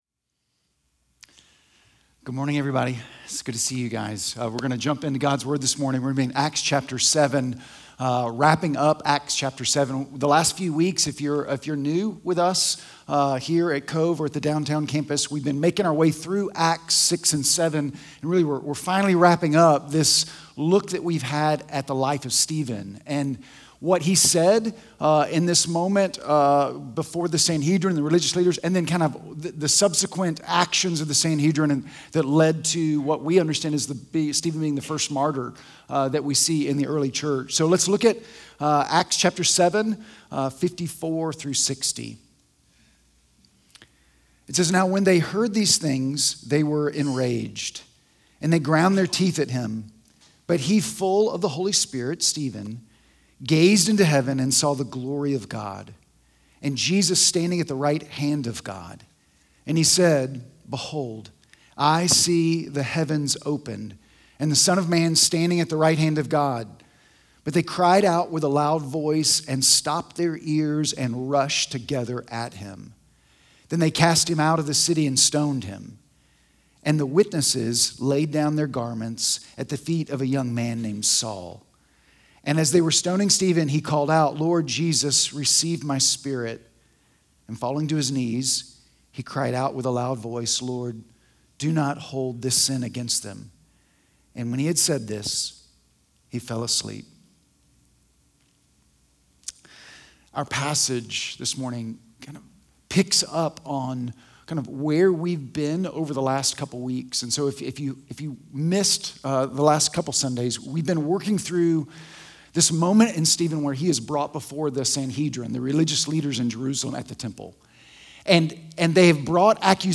Sermon Audio Sermon Notes…